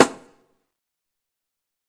DBDKII - AMG Snare.wav